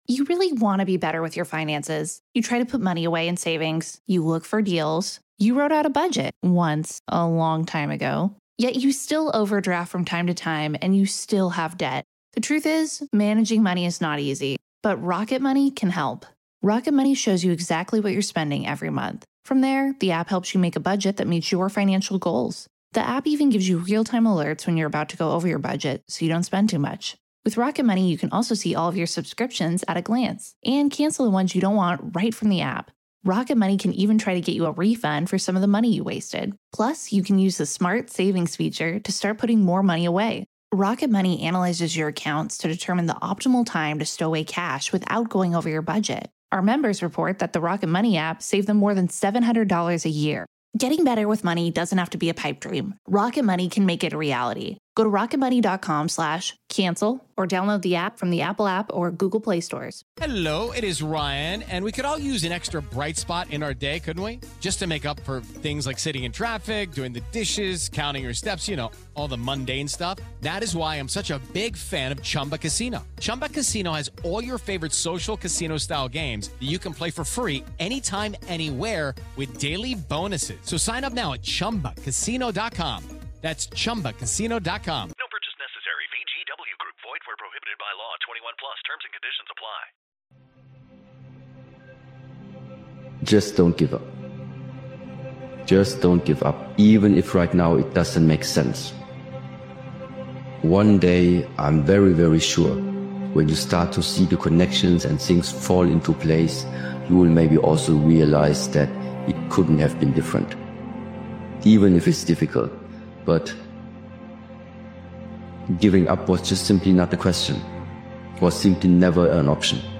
Powerful Motivational Speeches Video is a mindset-shifting motivational video created and edited by Daily Motivations. This impactful motivational speeches compilation reminds you that your thoughts shape your actions, your habits, and ultimately your entire life.